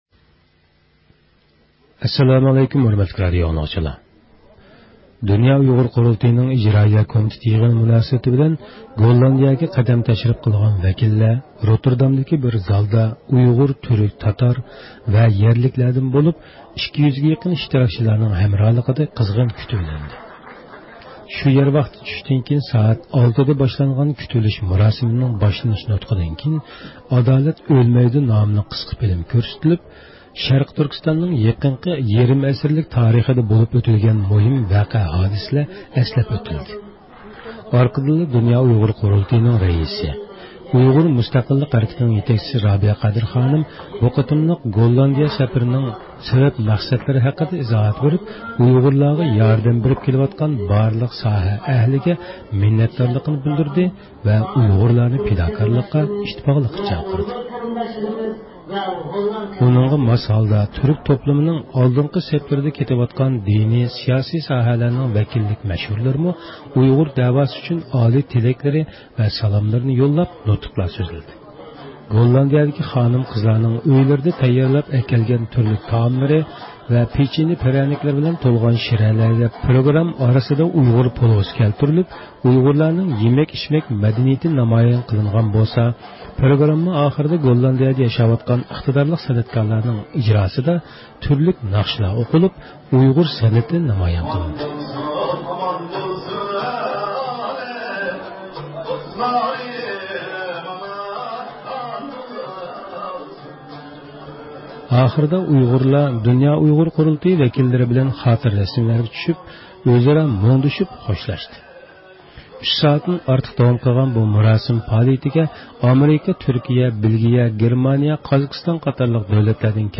رابىيە قادىر خانىم زىيارىتىمىزنى قوبۇل قىلىپ، بۇ قېتىملىق يېغىننىڭ گوللاندىيىدە ئېچىلىشىدىكى سەۋەب-مەقسەتلىرى ھەققىدە تەپسىلىي توختالدى.